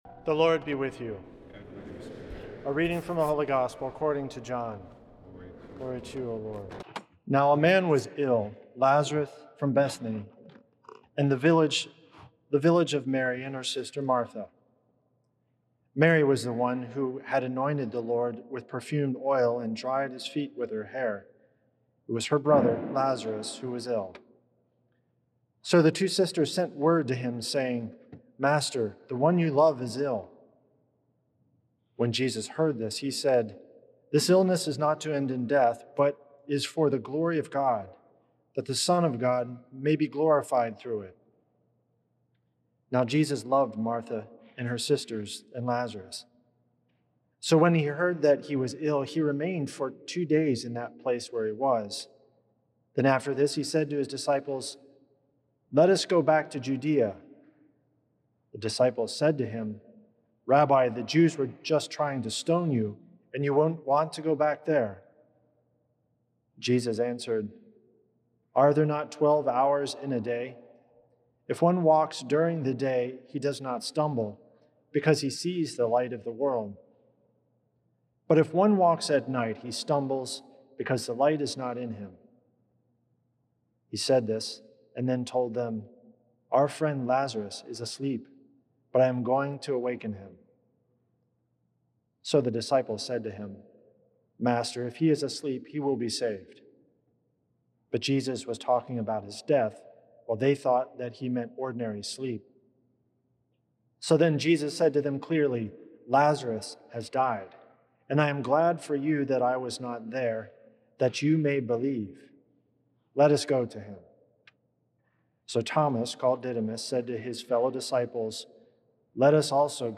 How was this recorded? at St. Patrick’s Old Cathedral in NYC.